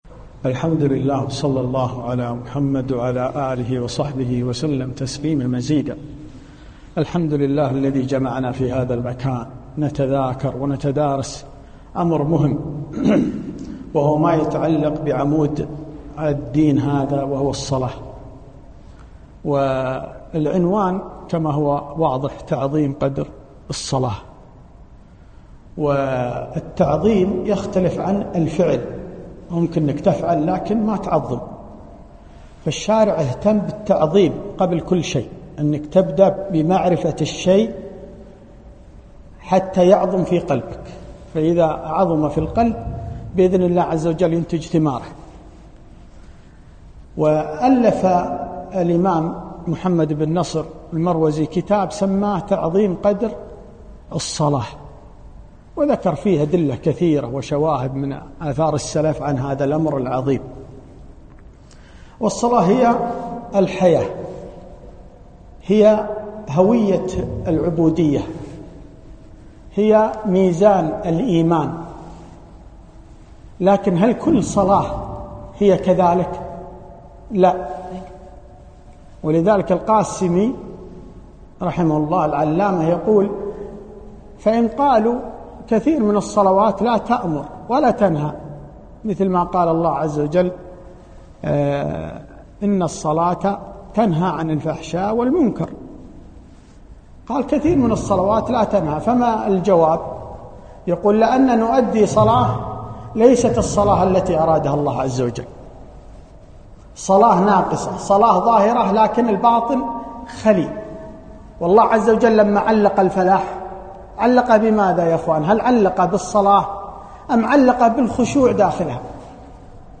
محاضرة - قدر تعظيم الصلاة